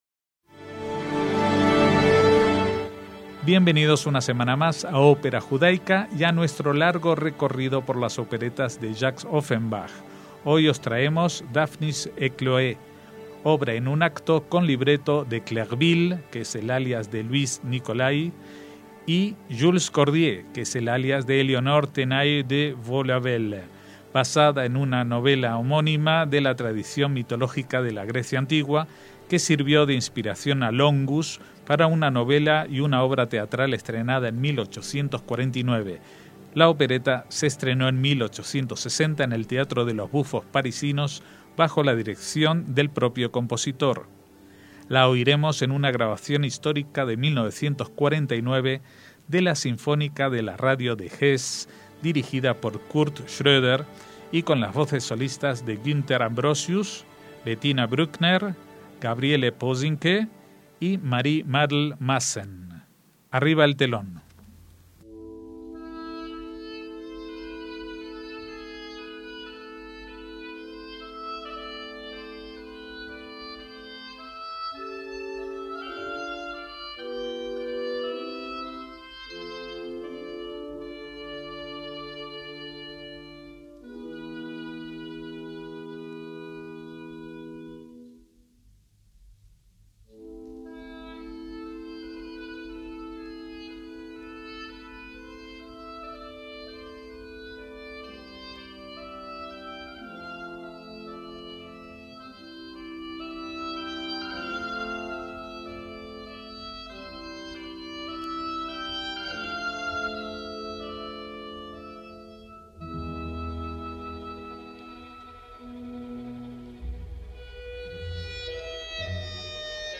es una opereta en un acto
en una grabación de 1949
voces solistas